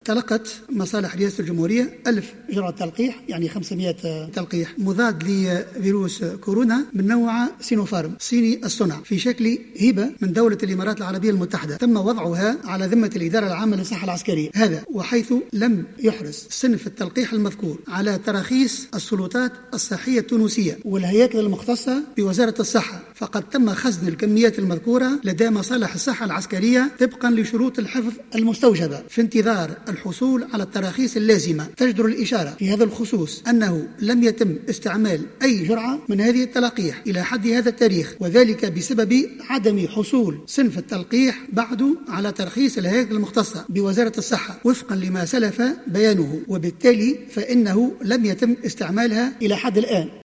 وأكد خلال جلسة استماع له في البرلمان، أنه لم يتم استعمال أي جرعة من هذا اللقاح إلى حدّ اليوم وذلك بسبب عدم حصوله على ترخيص وزارة الصحة التونسية.